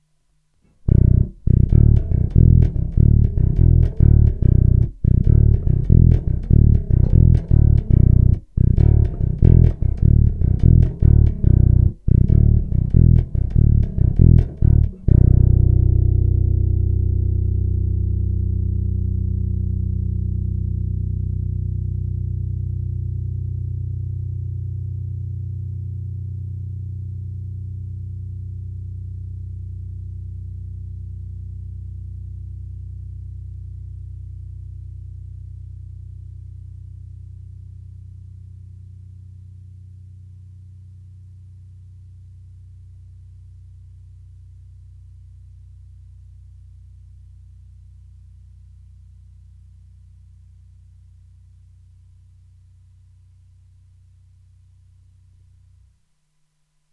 Myslel jsem si, že zvukový rozdíl jedna versus druhá cívka díky jejich malé vzdálenosti nebude vůbec znatelný, ale přeci jen trochu jiná barva zvuku tam je, jak můžete slyšet z níže uvedených ukázek, které jsou provedeny rovnou do zvukovky a jinak ponechány kromě normalizace bez jakýchkoli dodatečných úprav. Použité struny jsou neznámé niklové pětačtyřicítky ve skoro novém stavu a se slušným zvukem.
Struna H mě příjemně překvapila, že to není úplné bláto, ale celkem slušně hraje. Sustainu má také na rozdávání, to dělá ten masívní krk.
Struna H s oběma cívkami